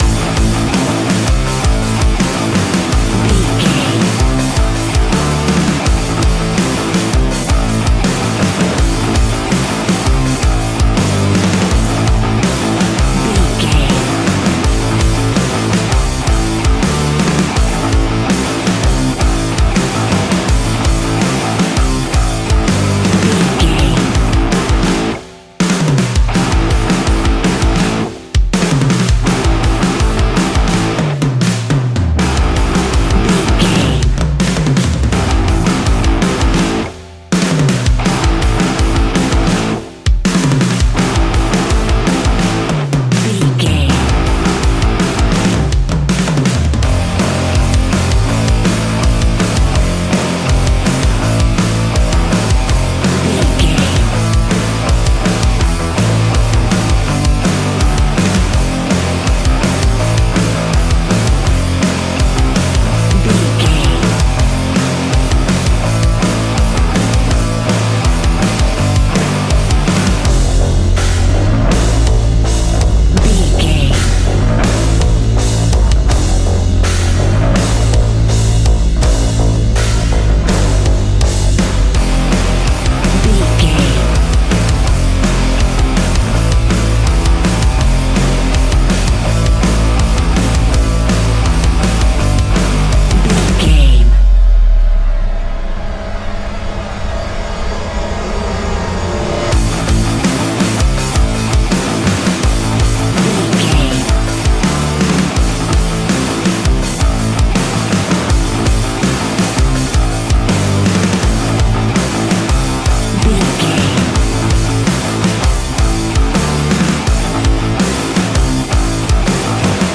Ionian/Major
F♯
hard rock
guitars
heavy metal
instrumentals